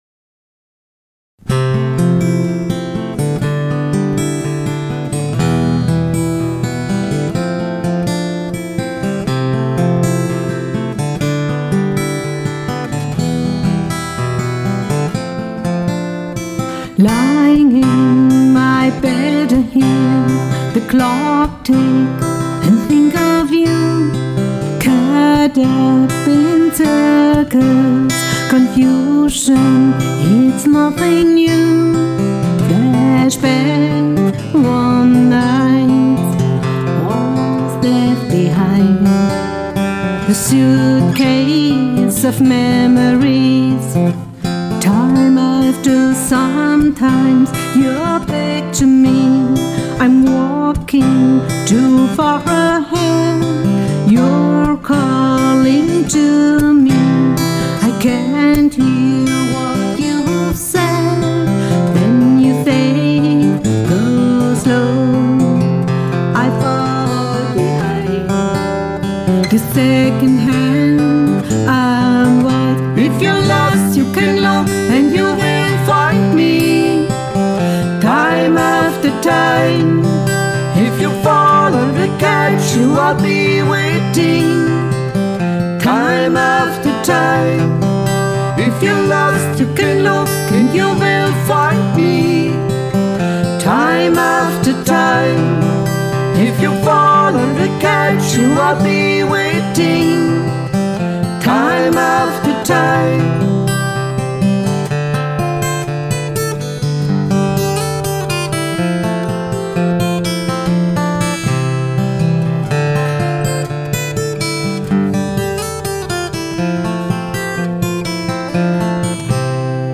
Acoustic Pop. New Country und Irish Folk
• Duo/Trio/Quartett
• Coverband
• Gitarristen